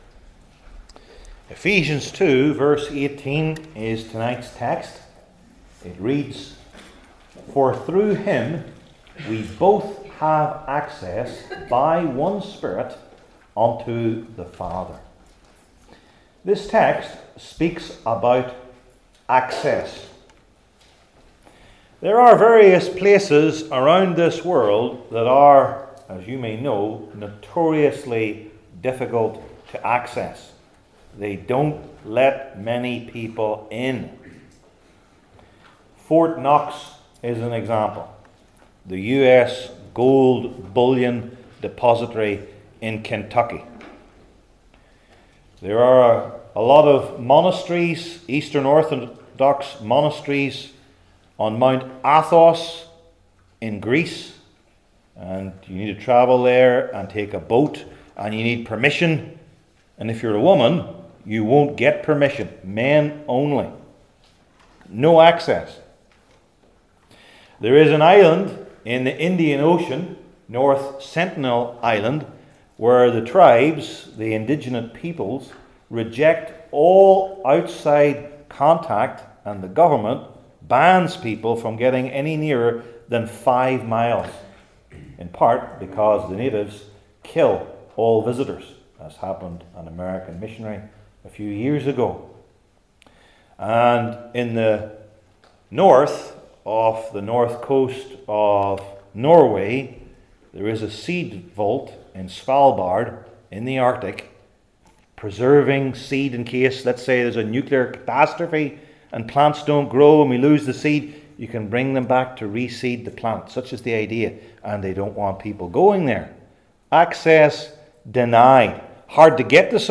New Testament Sermon Series I. What?